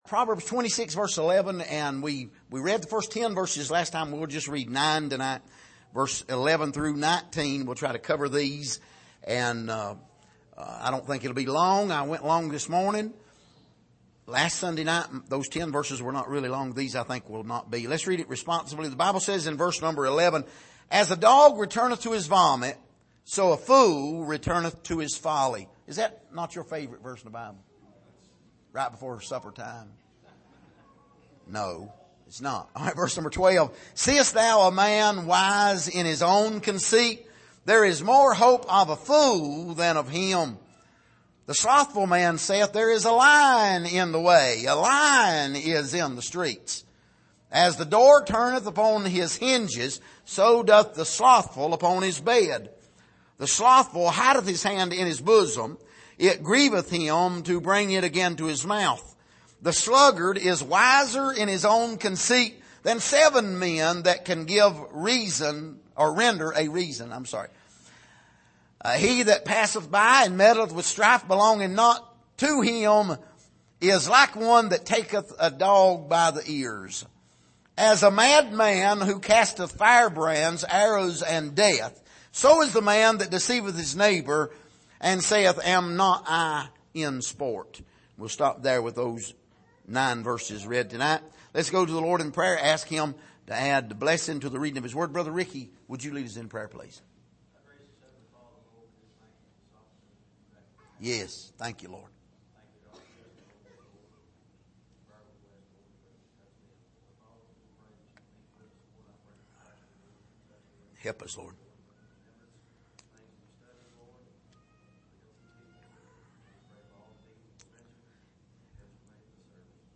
Passage: Proverbs 26:11-19 Service: Sunday Evening